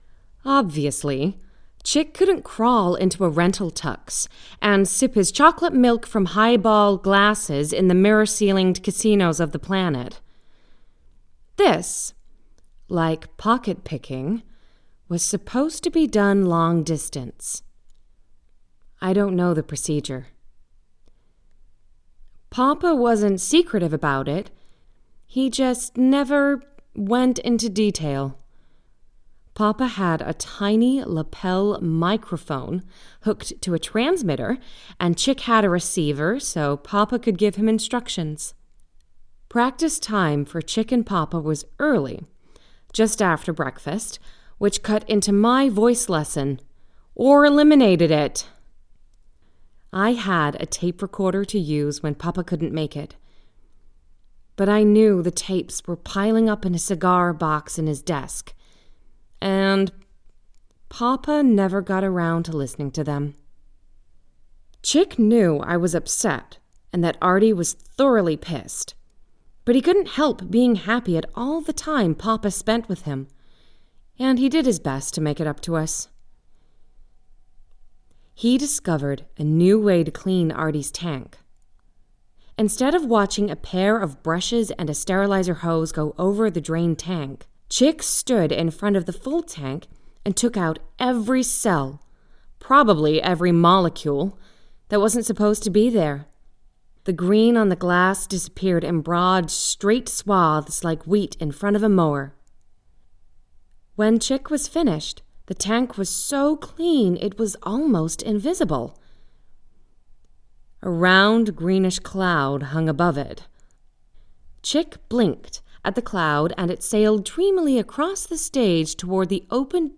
Female
Warm, buttery, smooth voice with ample capability to energise and deliver bright reads too. Great skill in delivering with a conversational tone to a more intimate read.
Audiobooks
American Accented Book Read
All our voice actors have professional broadcast quality recording studios.
0510General_American_Book_Read.mp3